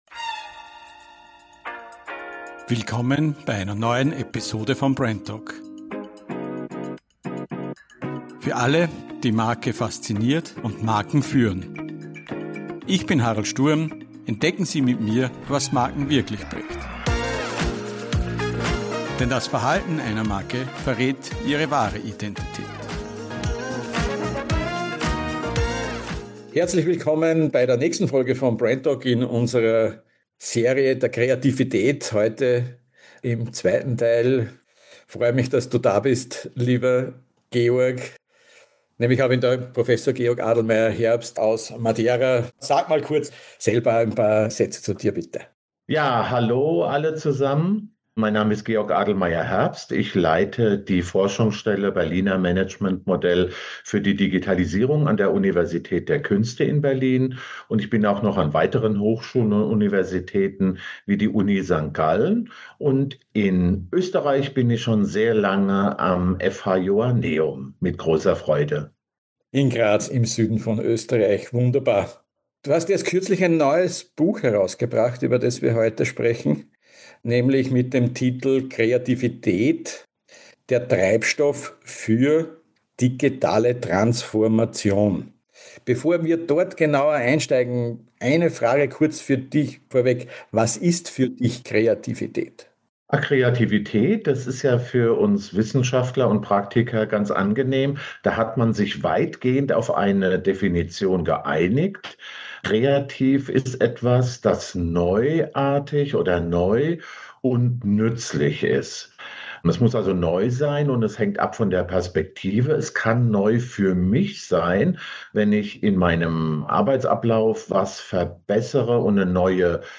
live zugeschaltet aus Madeira